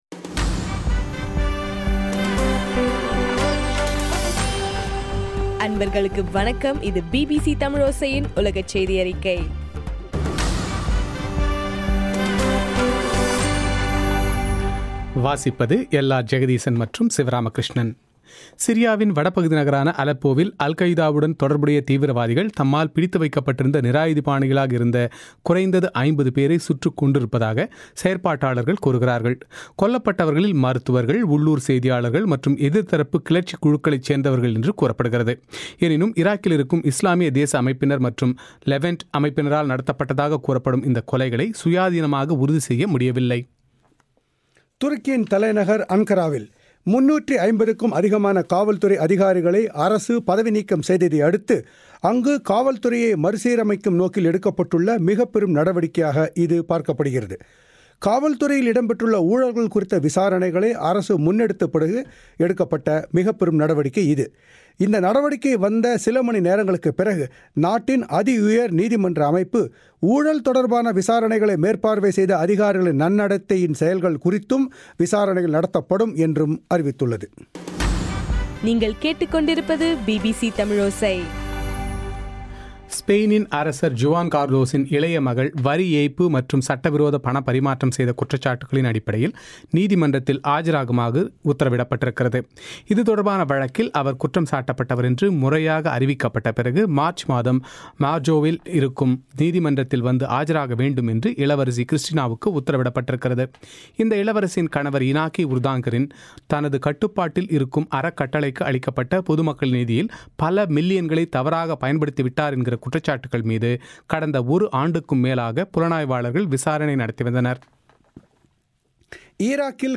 பிபிசி தமிழோசையின் உலகச் செய்தியறிக்கை- ஜனவரி 7